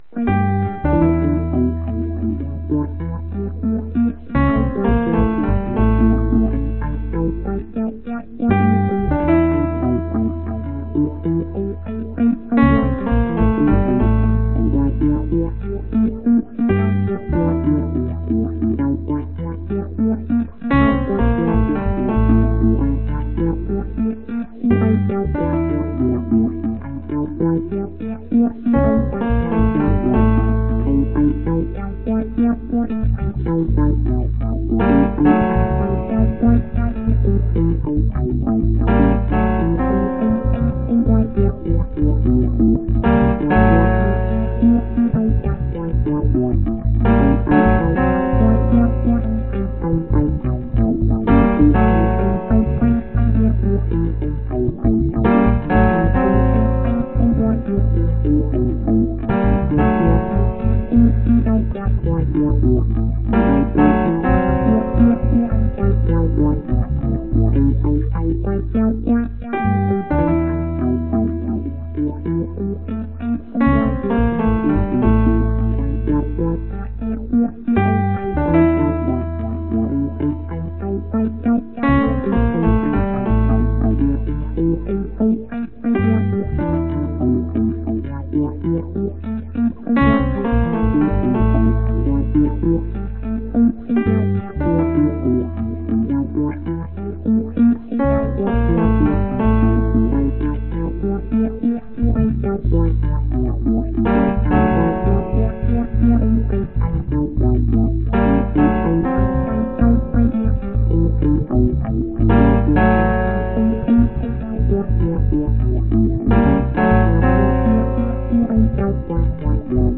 严重扰乱了7/8节拍。